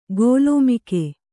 ♪ gōlōmike